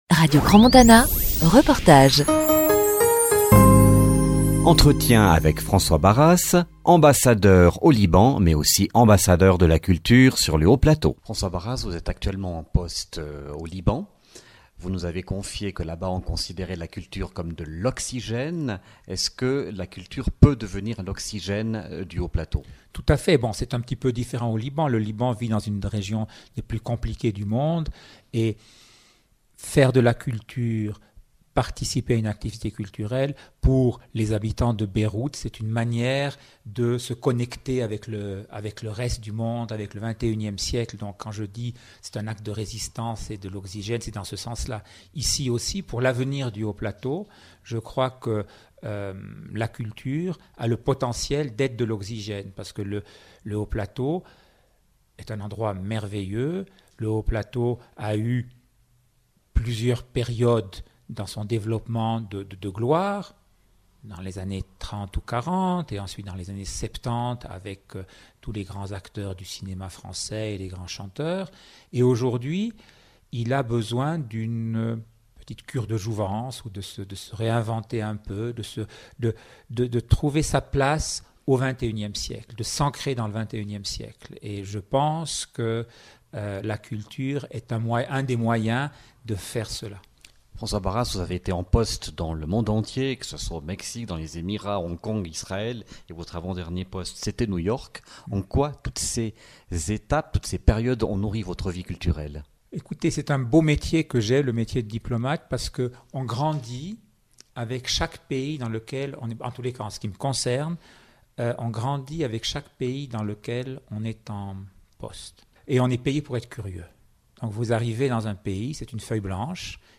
Interview de François Barras, Ambassadeur de Suisse au Liban (Partie 2 enregistrée le 9 janvier 2015)